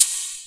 PERC - GUESS.wav